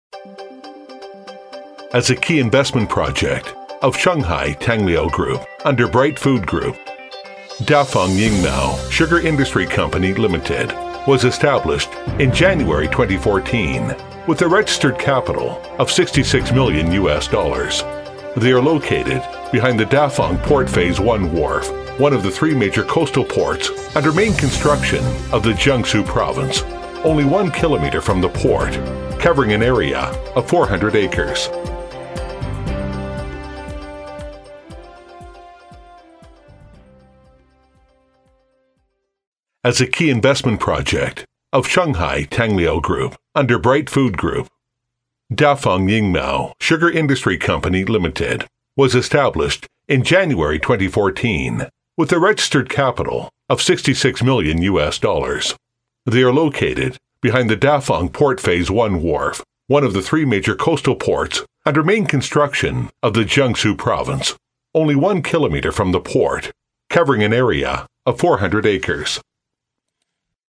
• T110-1 美式英语 男声 激情激昂|大气浑厚磁性|沉稳|低沉|娓娓道来